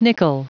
Prononciation du mot nickel en anglais (fichier audio)
Prononciation du mot : nickel